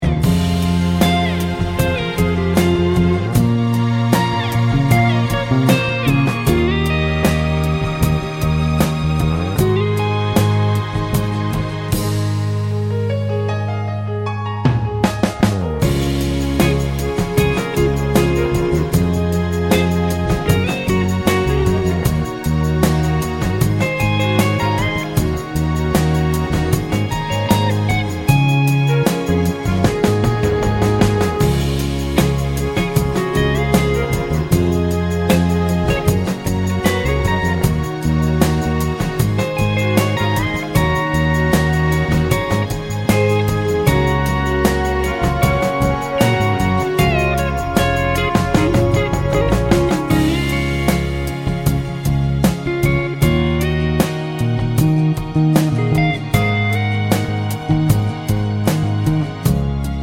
no Backing Vocals Crooners 3:34 Buy £1.50